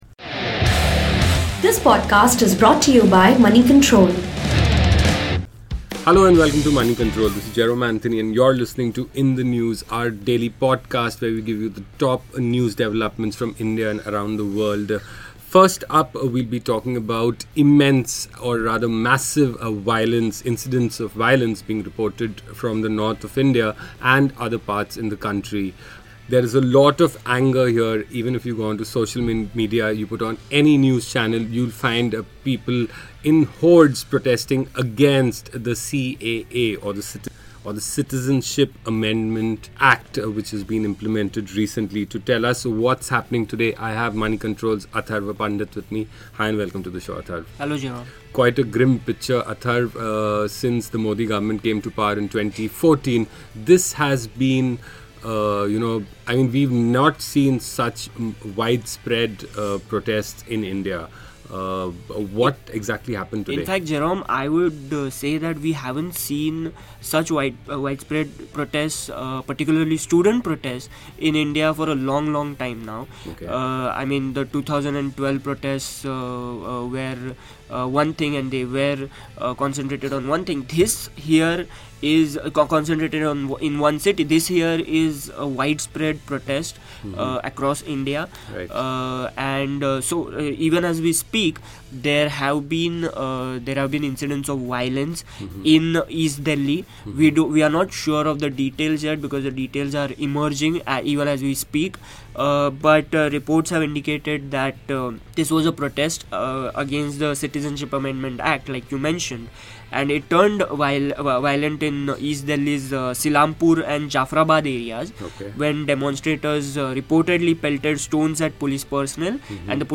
gets in conversation with colleagues from the news room to find out the top stories of the day. From nation-wide protests against the controversial amended Citizenship Act to former Pakistani president Pervez Musharraf being handed the death sentence, here are the top news stories of the day.